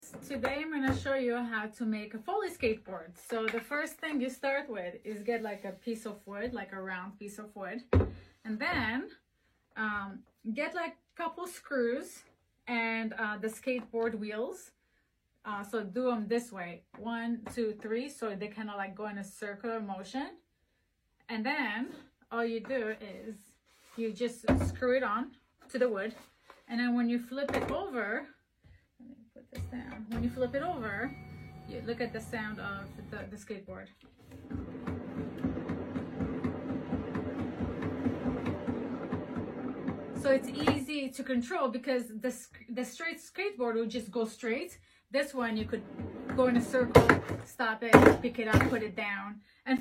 328 How to make a Foley skateboard on a flexispot com